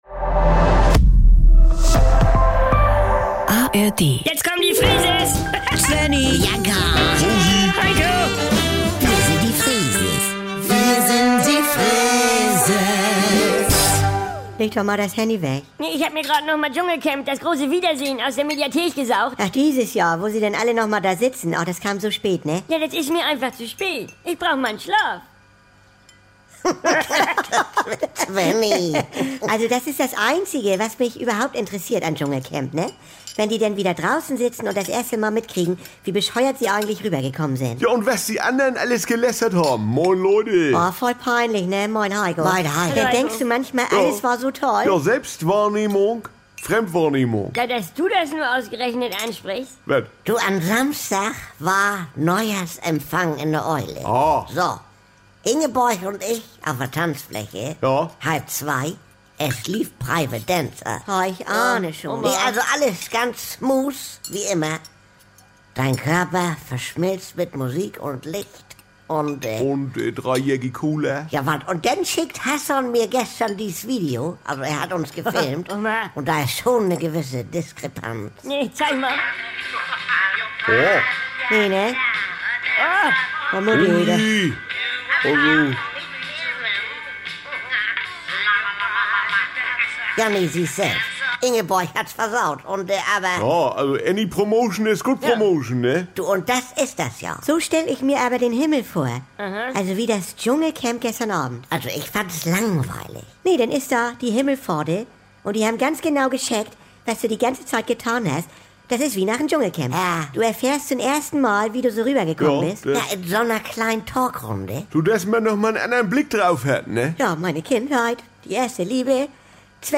Das Einzige, was Bianca am Dschungelcamp interessiert, ist, wenn die Kandidaten rauskommen und merken, wie über sie gelästert wurde. Jederzeit und so oft ihr wollt: Die NDR 2 Kult-Comedy direkt aus dem Mehrgenerationen-Haushalt der Familie Freese.